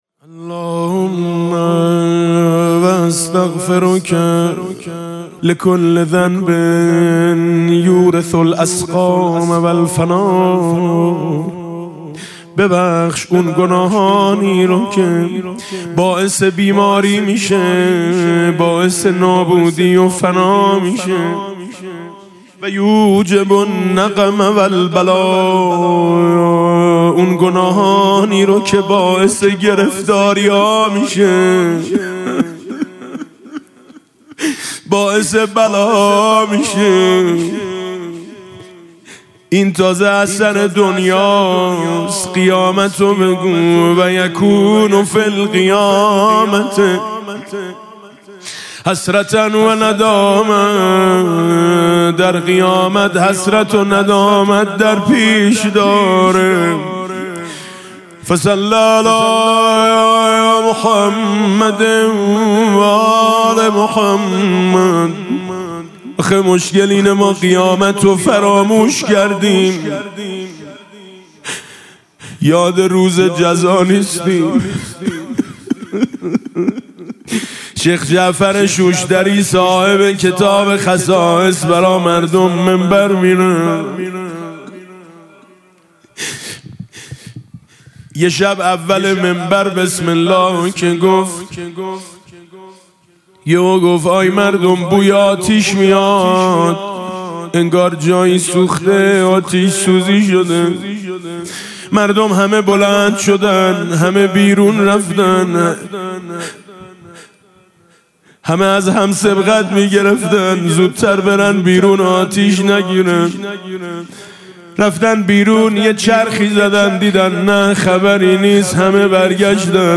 استغفار هفتاد بندی امام علی علیه السلام با نوای میثم مطیعی + متن (بند بیست و ششم) | ضیاءالصالحین